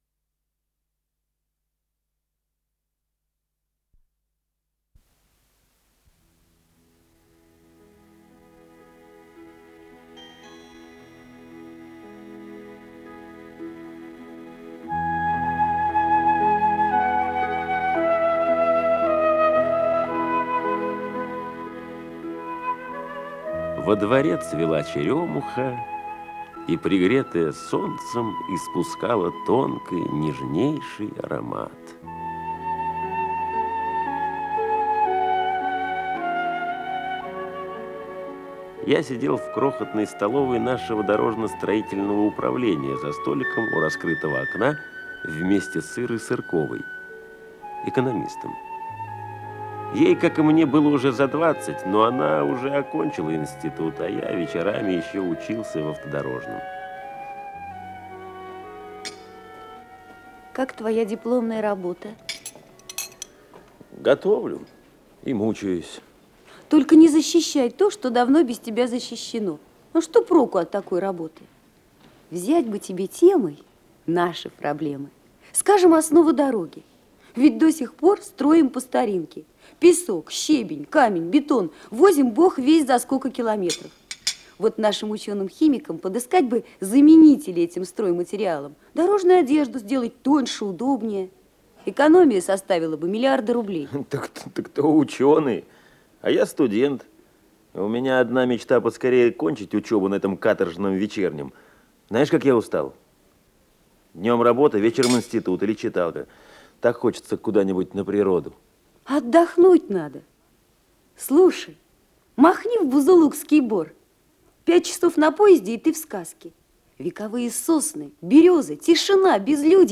Исполнитель: Артисты московских театров
Радиопостановка, передача 1-я